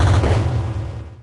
Techmino/media/effect/chiptune/clear_5.ogg at 89134d4f076855d852182c1bc1f6da5e53f075a4
clear_5.ogg